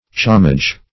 Search Result for " chomage" : The Collaborative International Dictionary of English v.0.48: Chomage \Cho`mage"\, n. [F. chomage.] 1.